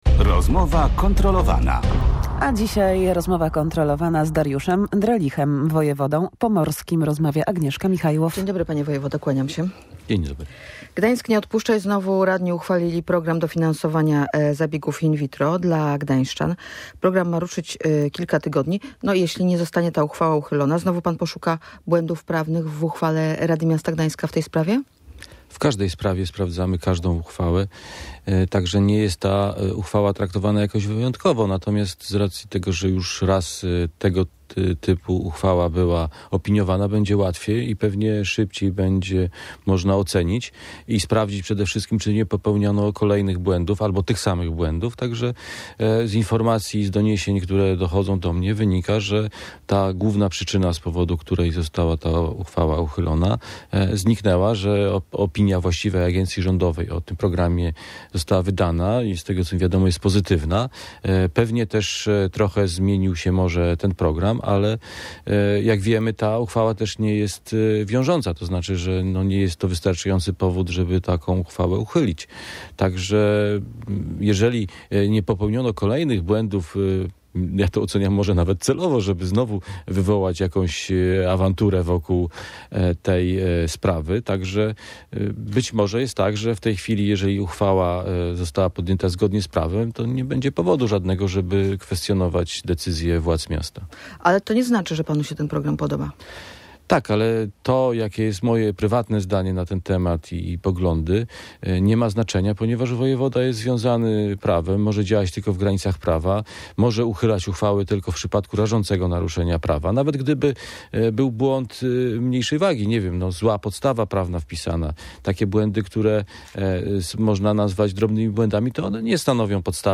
- Będę analizował tę uchwałę punkt po punkcie - zapowiedział w Rozmowie Kontrolowanej wojewoda pomorski Dariusz Drelich.